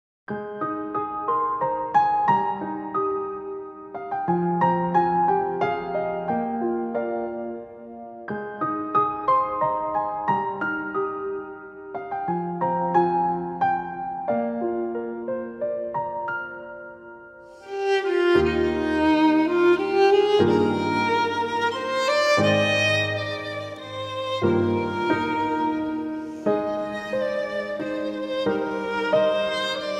Instrumental
Violinistin